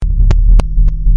Descarga de Sonidos mp3 Gratis: sintetizador 16.